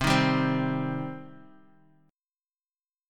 Listen to Cm strummed